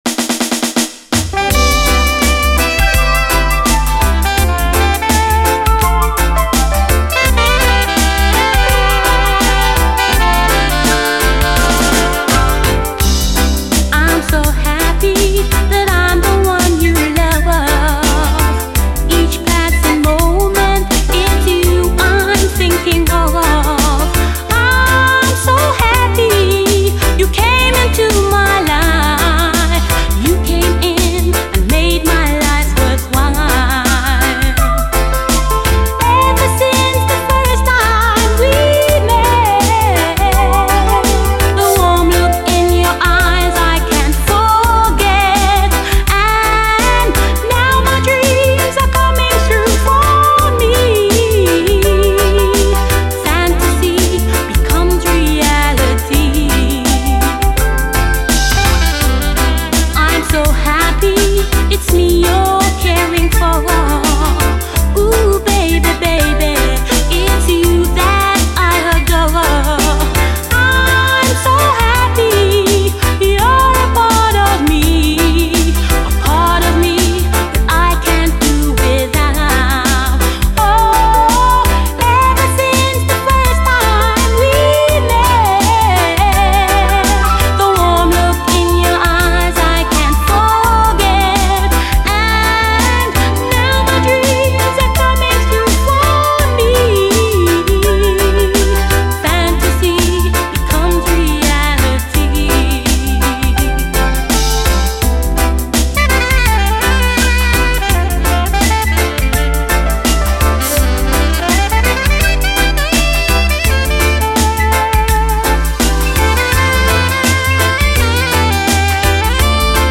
REGGAE
ジャマイカ録音